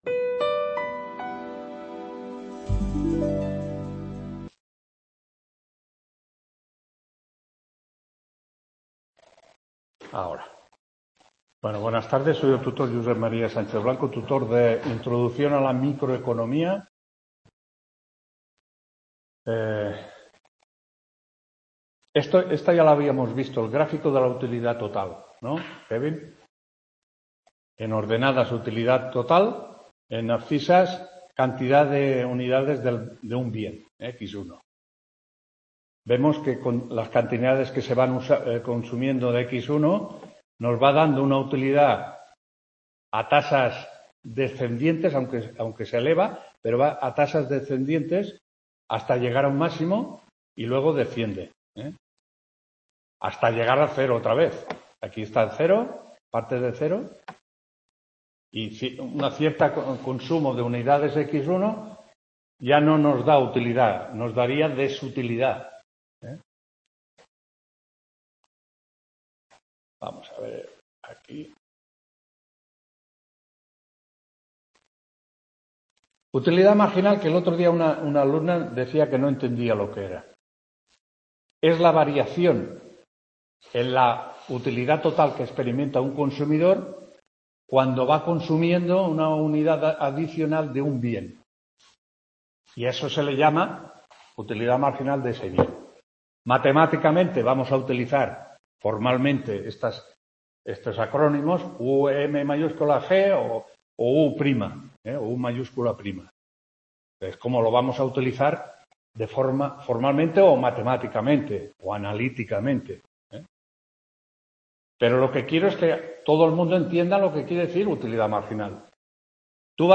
4ª TUTORÍA INTRODUCCIÓN A LA MICROECONOMÍA 29-10-24 …